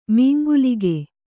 ♪ māngaḷika